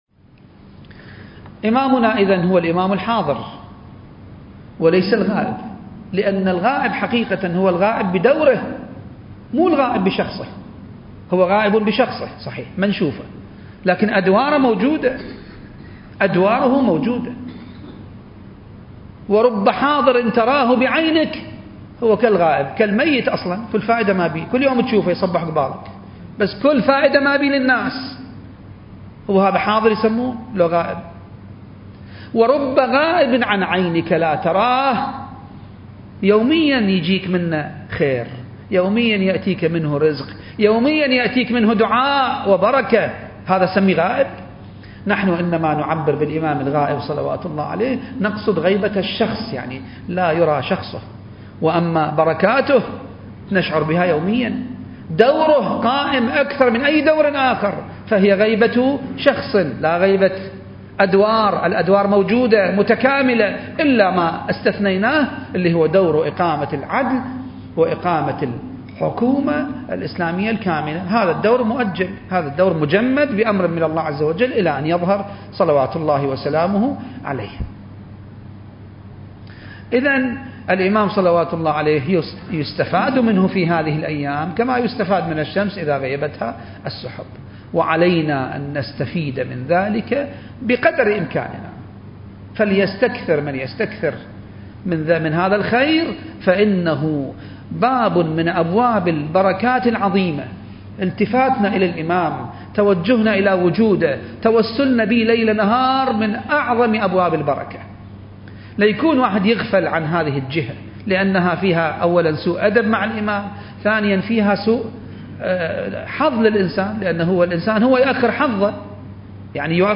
المكان: جامع الصاحب (عجّل الله فرجه) - النجف الأشرف التاريخ: 2021